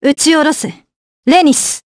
Isolet-Vox_Skill1_jp.wav